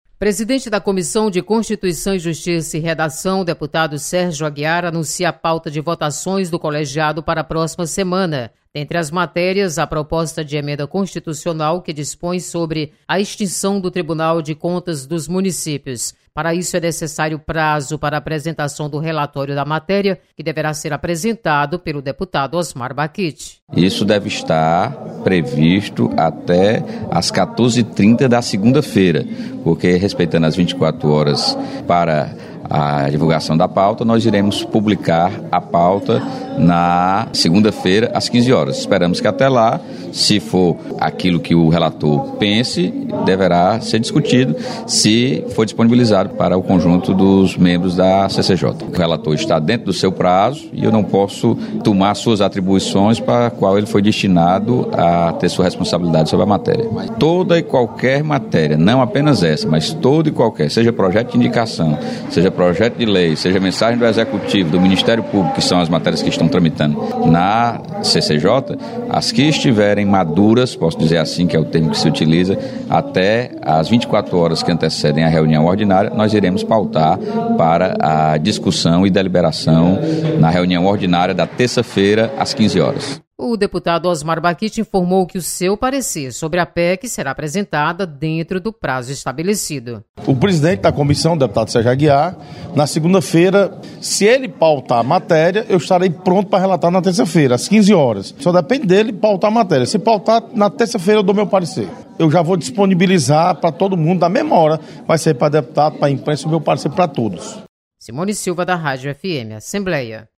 CCJ tem definida pauta de votações. Repórter